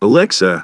synthetic-wakewords
ovos-tts-plugin-deepponies_Nameless Hero_en.wav